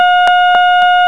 troubling sound you will hear more often than you'd like...but which you can turn off easily.
satalarm.au